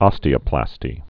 (ŏstē-ə-plăstē)